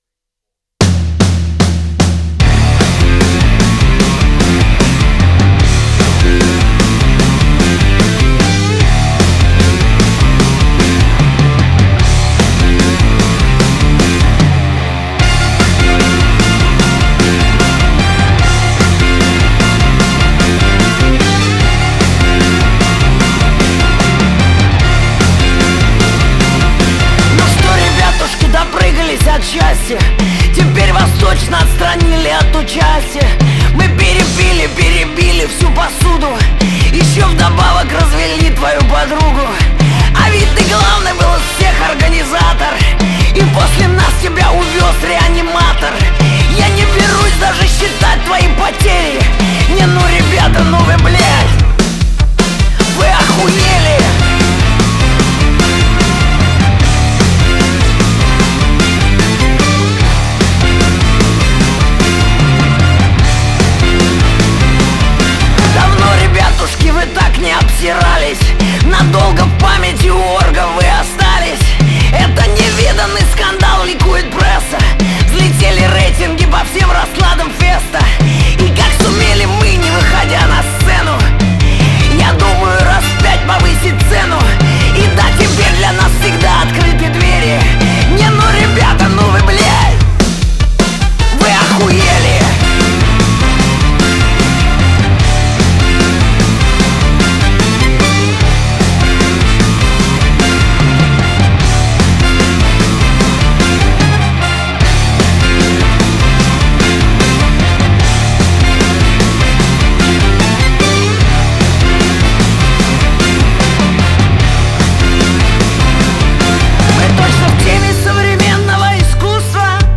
это яркая и энергичная песня в жанре поп-рок